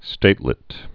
(stātlĭt)